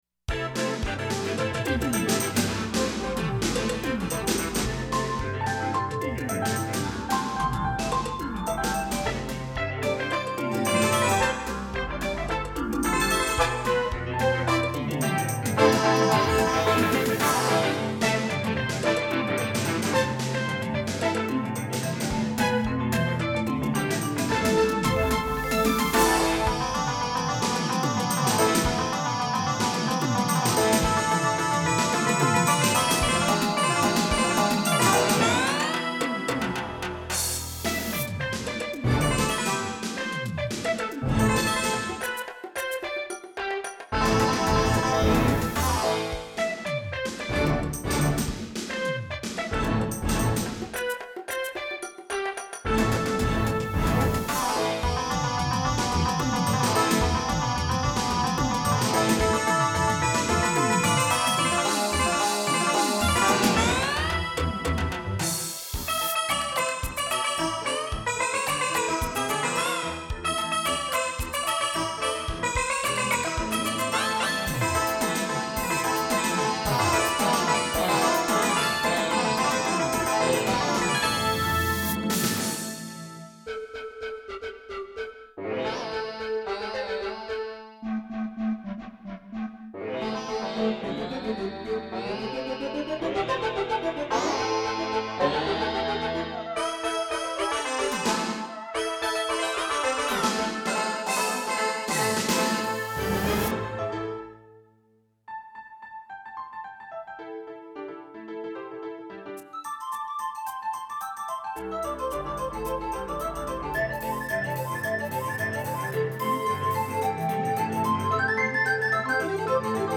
ポップス　その他　　 ファイル名